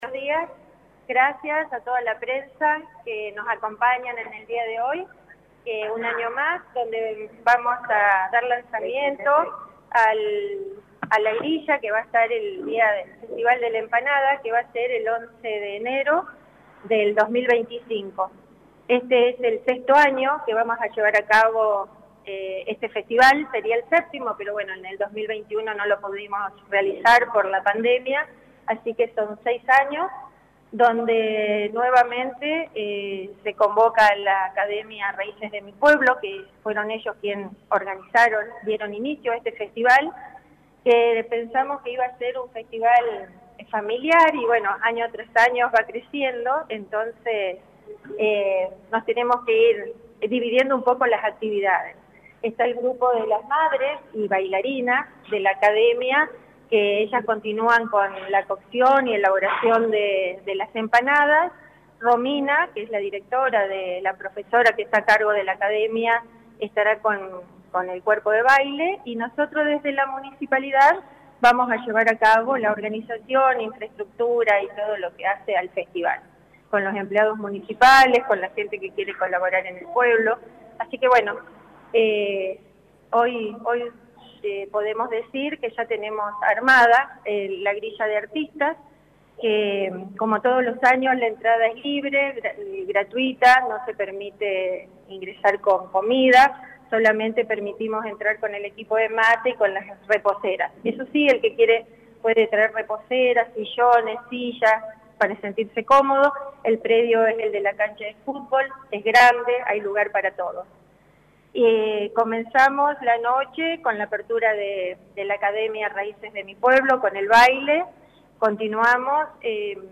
AUDIO - CONFERENCIA DE PRENSA